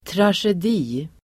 Ladda ner uttalet
Uttal: [trasjed'i:]